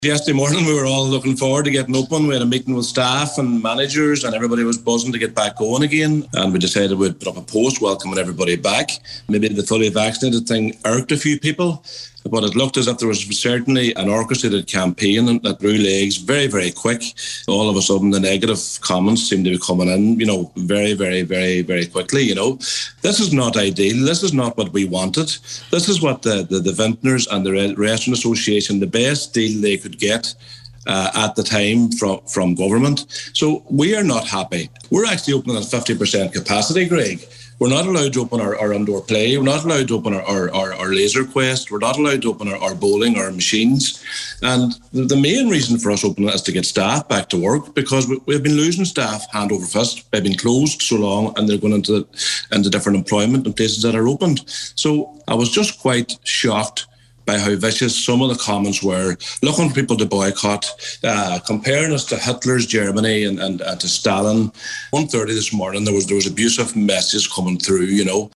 told today’s Nine til Noon Show that he was taken aback by how vicious the comments were: